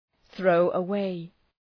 Προφορά
{,ɵrəʋə’weı}
throw-away.mp3